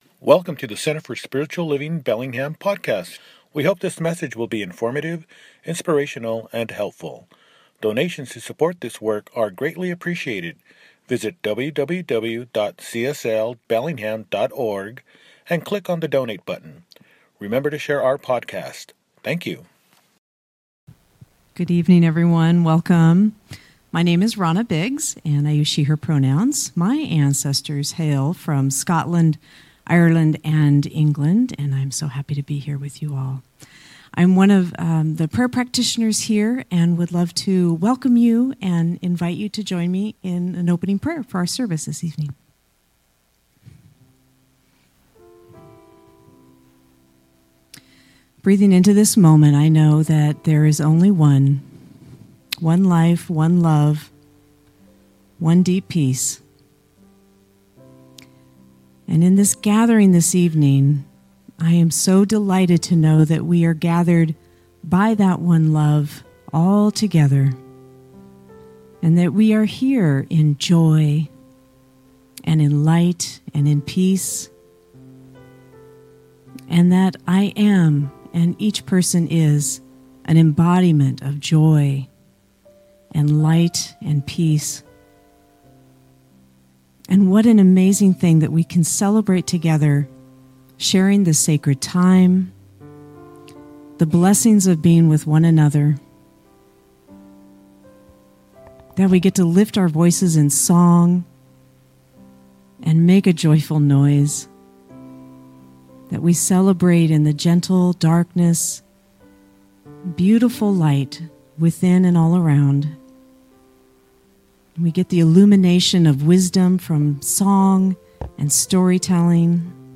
Christmas Eve Candle Lighting Service
Christmas-Eve-Candlelight-Service-podcast.mp3